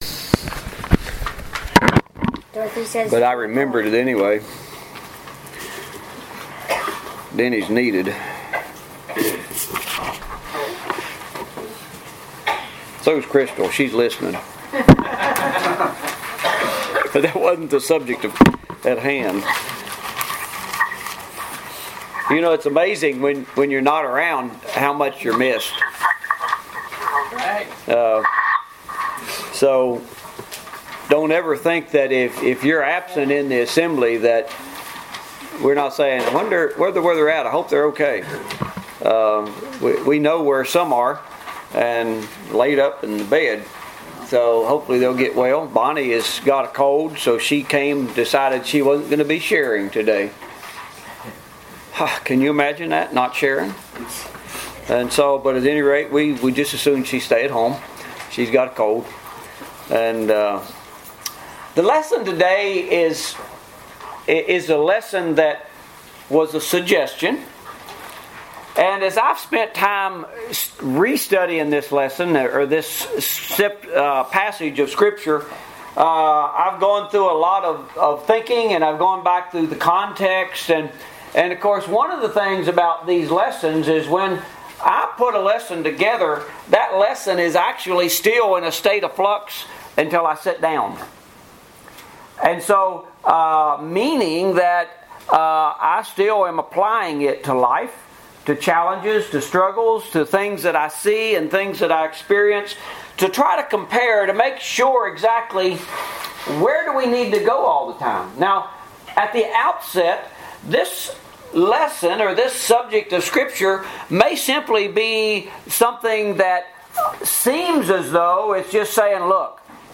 Practicing Humility, Part 1 Bible , humble , judging , scripture , sermon Post a comment Cancel Reply You must be logged in to post a comment.